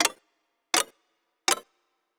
Countdown (5).wav